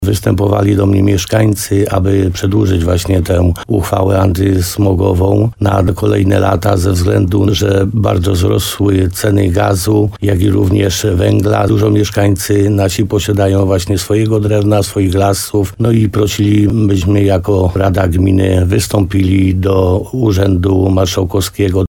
Wójt Piotr Stach przekonywał w porannej rozmowie RDN Nowy Sącz, że wymiana pieców na gazowe postępuje na tyle dobrze w jego gminie, że widać to w poprawie jakości powietrza w okolicy.